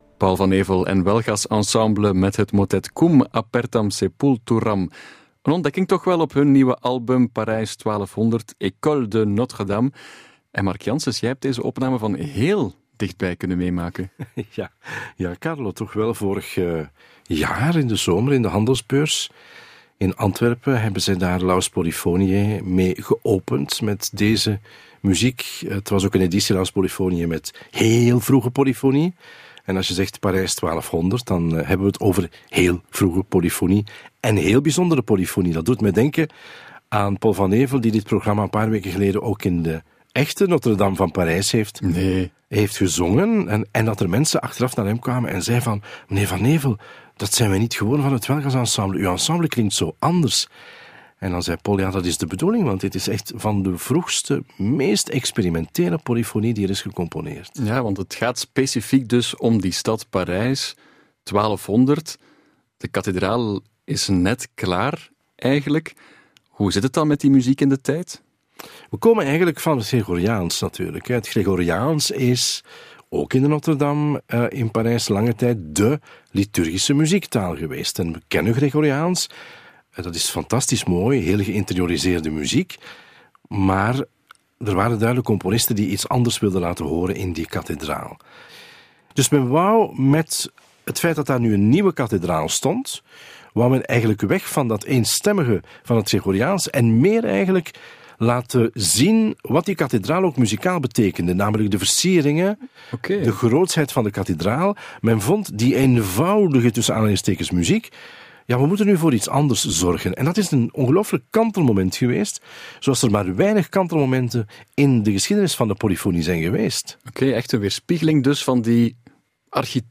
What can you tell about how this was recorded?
Onze nieuwste cd-opname